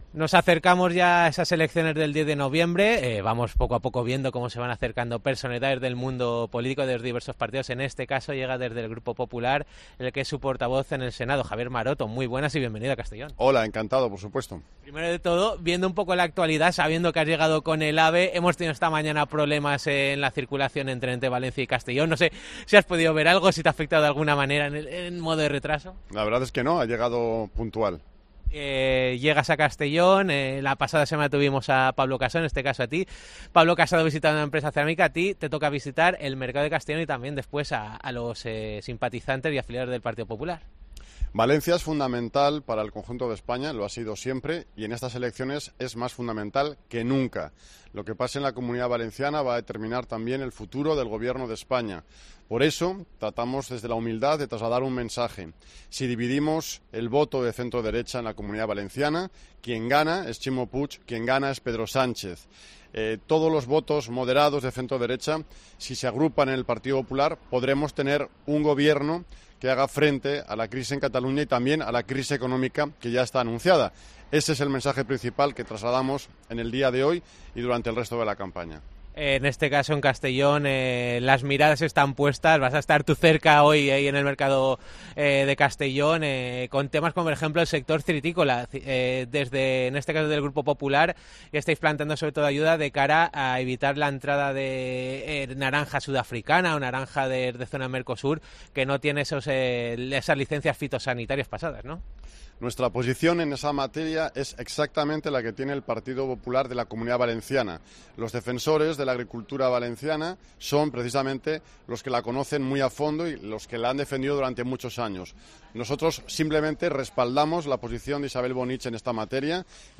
Entrevista a Javier Maroto, portavoz del Partido Popular en el Senado
Antes, ha atendido a los micrófonos de la Cadena COPE donde ha destacado que “la Comunidad Valenciana es más fundamental que nunca en estas elecciones. Si dividimos el voto de centro-derecha quien gana es Pedro Sánchez. Si se agrupa, un gobierno que haga frente a Cataluña y la crisis”.